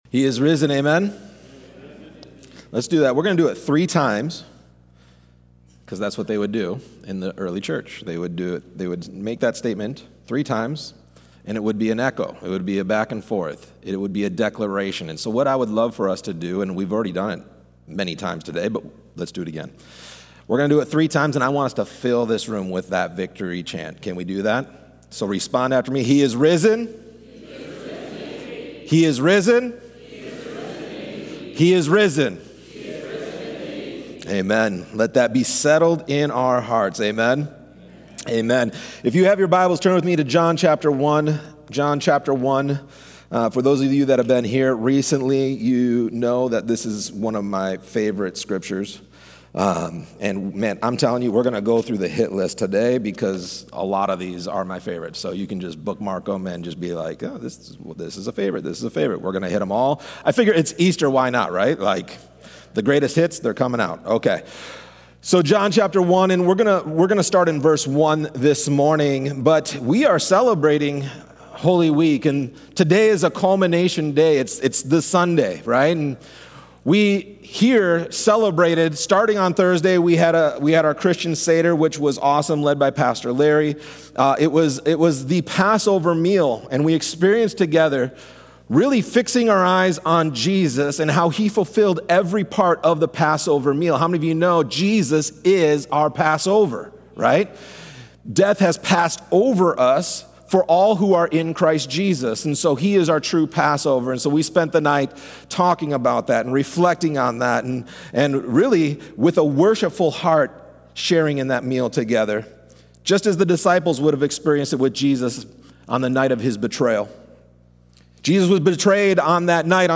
A message from the series "Names Of God."